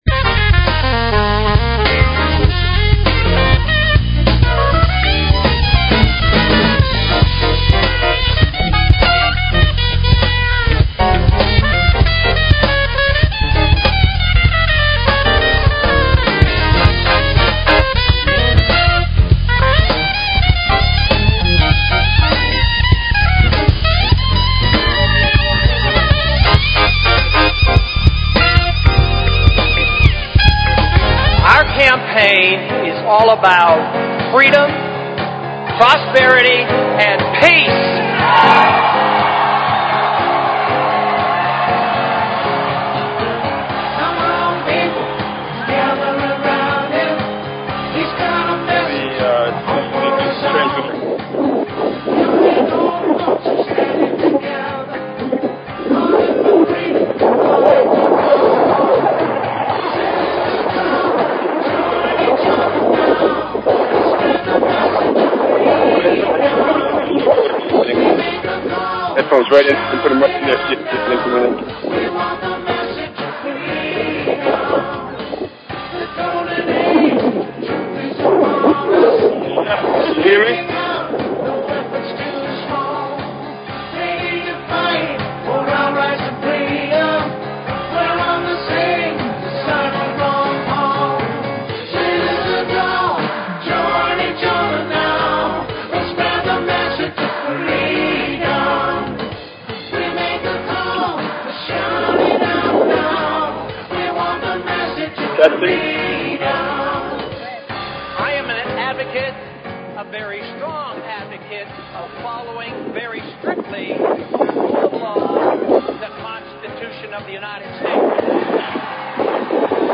Talk Show Episode, Audio Podcast, The_Freedom_Message and Courtesy of BBS Radio on , show guests , about , categorized as
It's a live internet radio call in show for and about the Ron Paul Revolution. It serves as a media outlet for campaign announcements and news, a vehicle for the organization of Ron Paul support, a medium for the dissemination of ideas and tactics and a tool for the mobilization of large numbers of supporters in the movement to elect Ron Paul as our next President.